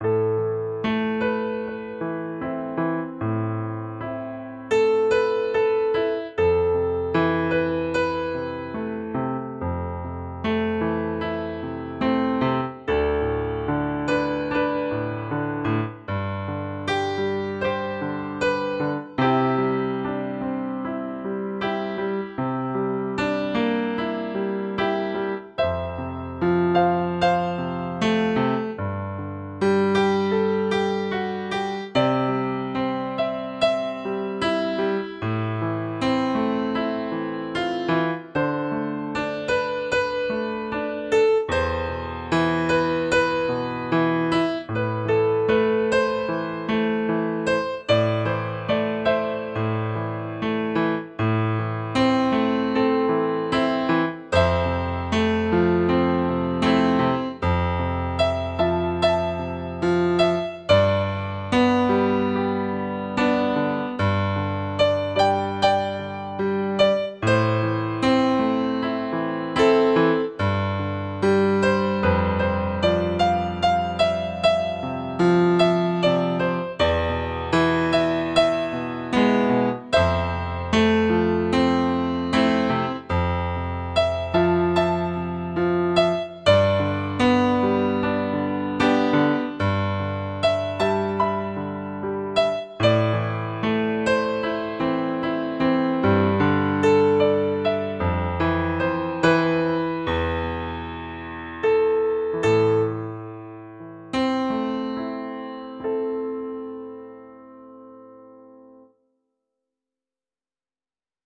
Grand Piano Vol 1 is a high resolution Grand Piano sampled in exquisite detail.
With 300MB of stereo samples driving the 5 multisamples, experience great dynamics and seamless switching between layers for a detailed and emotional response.
U-F 000 Stereo 5 Layer Piano (Demo 2)